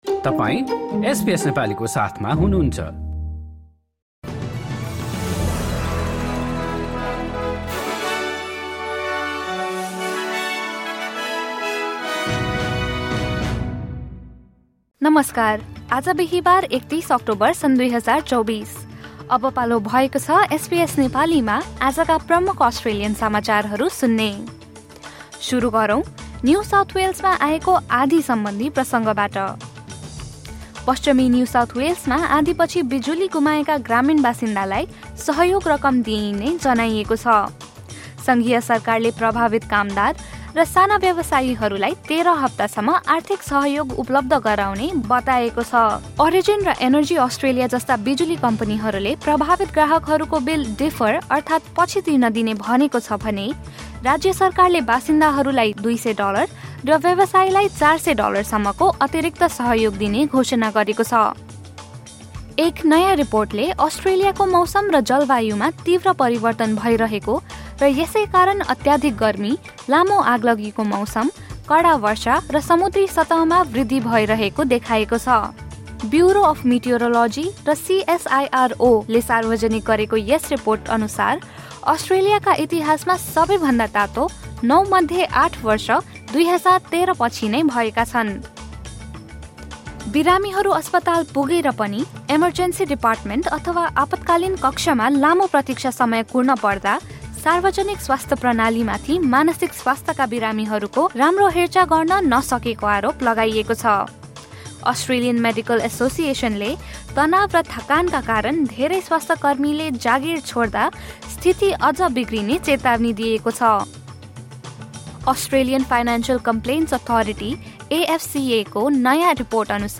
SBS Nepali Australian News Headlines: Thursday, 31 October 2024